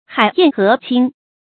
海晏河清 注音： ㄏㄞˇ ㄧㄢˋ ㄏㄜˊ ㄑㄧㄥ 讀音讀法： 意思解釋： 黃河水清；大海波平浪靜。